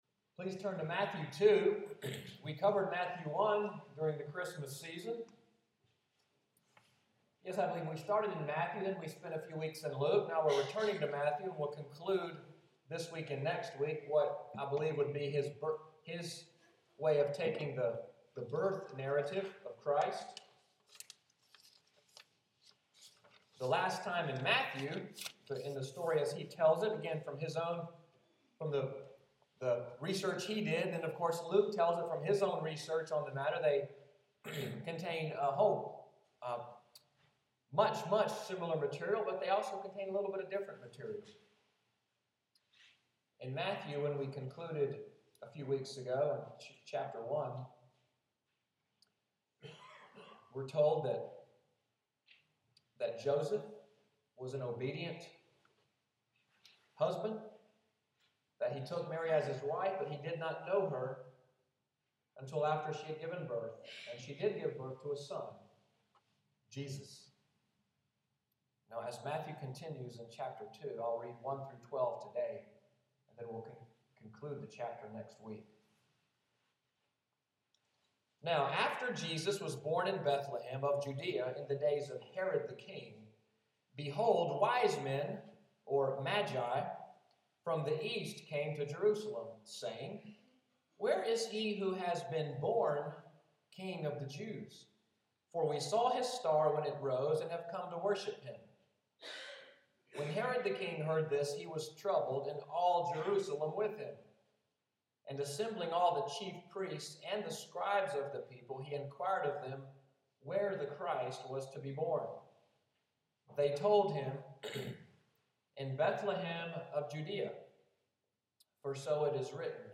Sunday’s sermon, “Worldwide Worship,” January 4, 2015